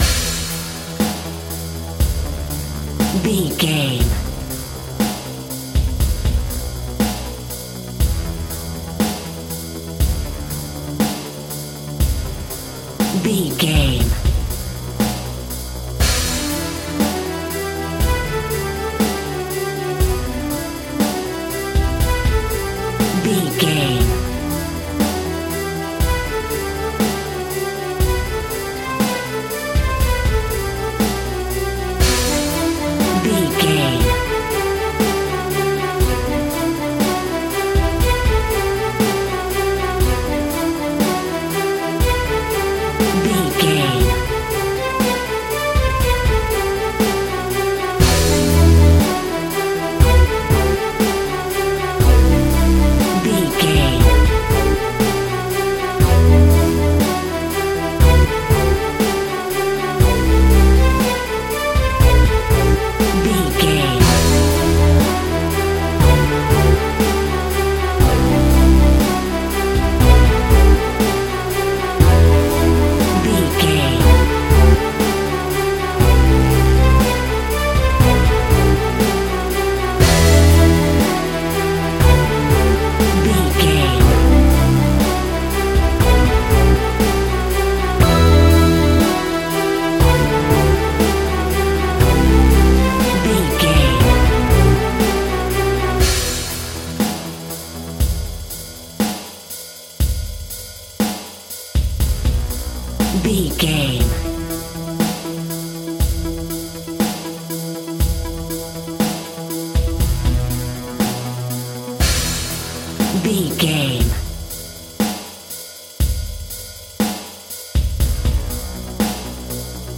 Pumped Up Dramatic Music.
In-crescendo
Aeolian/Minor
Fast
tension
ominous
eerie
staccato strings
Rock Drums
Rock Bass
Overdriven Guitar
Distorted Guitar